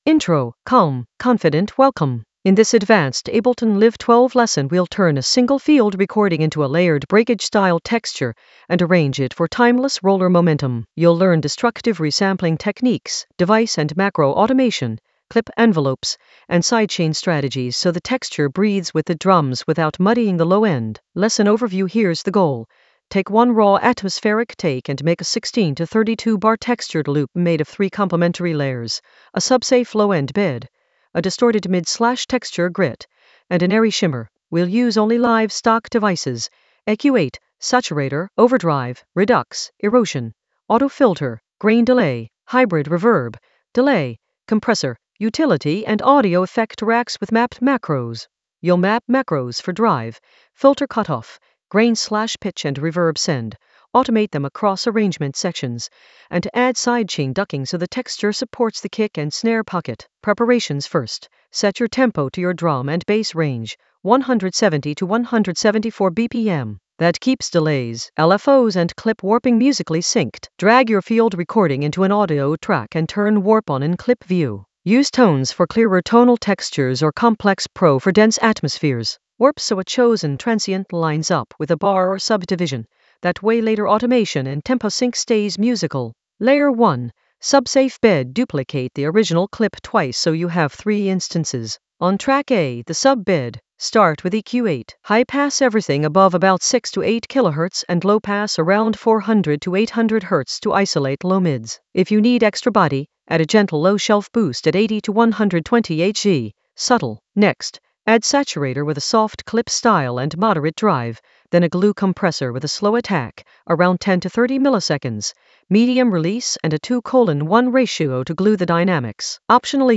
An AI-generated advanced Ableton lesson focused on Breakage field recording texture: distort and arrange in Ableton Live 12 for timeless roller momentum in the Automation area of drum and bass production.
Narrated lesson audio
The voice track includes the tutorial plus extra teacher commentary.